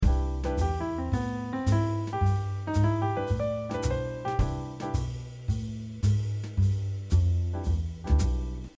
7. Using triplets
See how triplets are being used in the following example to make up the line.
Using-triplets.mp3